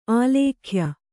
♪ ālēkhya